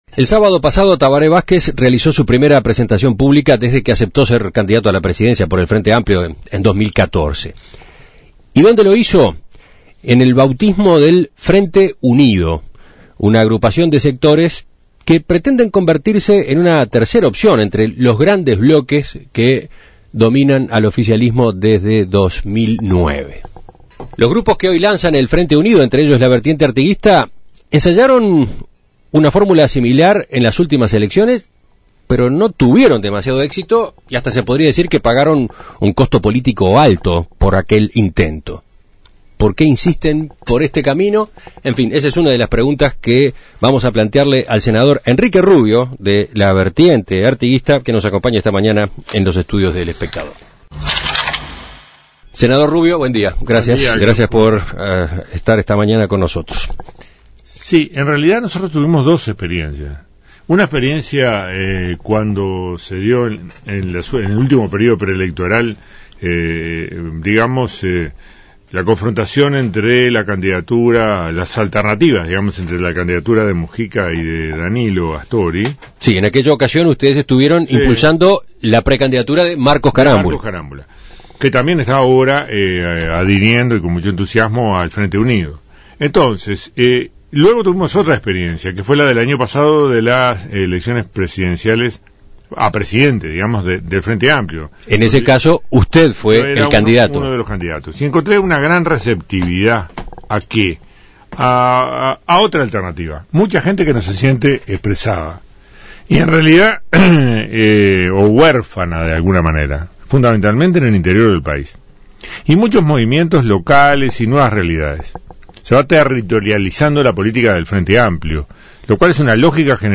El sábado pasado se presentó en sociedad el "Frente Unido", un nuevo agrupamiento de sectores del Frente Amplio (FA) integrado por la Vertiente Artiguista, la lista 5005 y el Movimiento de Alternativa Socialista. El senador de la Vertiente Artiguista Enrique Rubio dijo a En Perspectiva que se trata de un espacio "en construcción" que pretende ser una alternativa a la "polarización" entre el astorismo y el mujiquismo de los últimos años.
Entrevistas